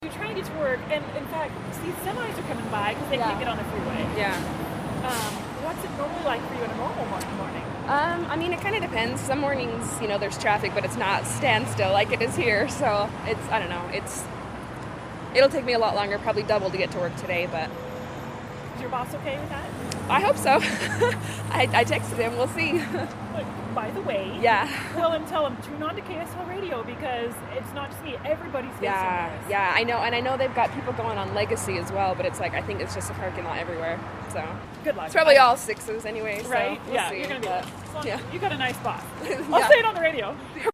I spoke with drivers at 2600 South and US 89 about trying to get to work with all the surface street backups.